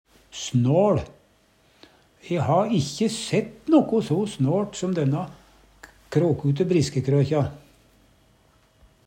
snåL - Numedalsmål (en-US)